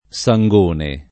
[ S a jg1 ne ]